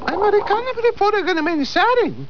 Pingu crying